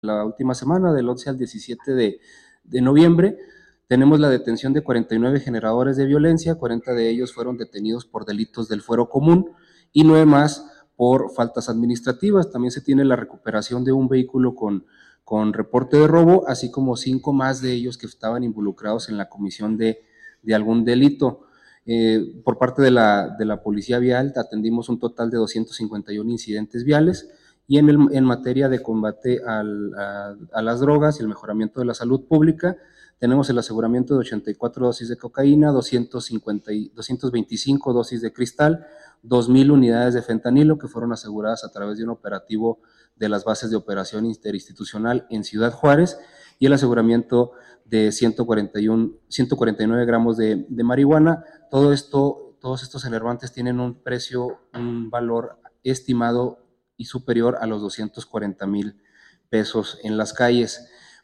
AUDIO: LUIS AGUIRRE, JEFE DE ESTADO MAYOR DE LA SECRETARÍA DE SEGURIDAD PÚBLICA DEL ESTADO (SSPE) 1